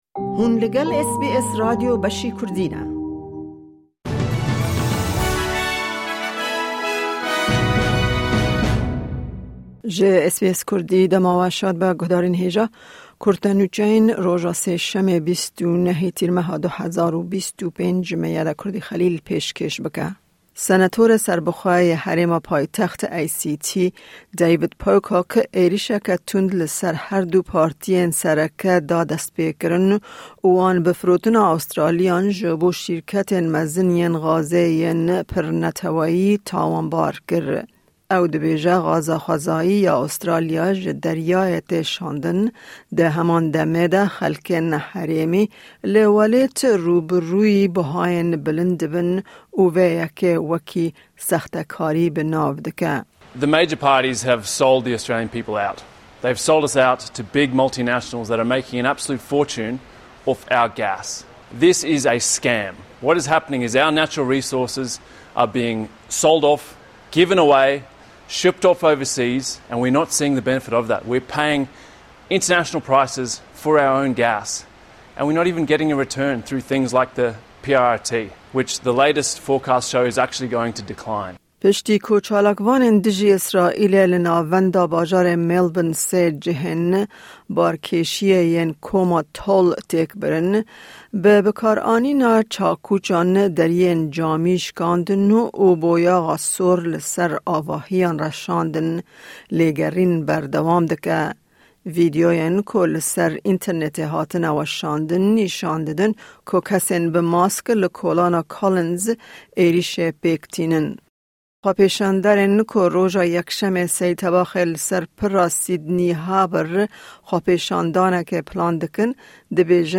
Kurte Nûçeyên roja Sêşemê 29î Tîrmeha 2025